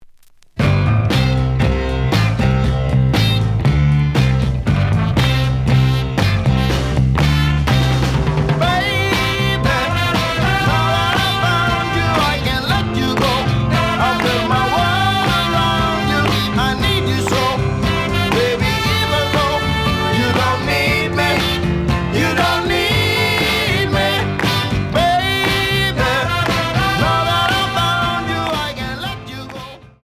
The audio sample is recorded from the actual item.
●Genre: Rock / Pop
Edge warp. But doesn't affect playing. Plays good.)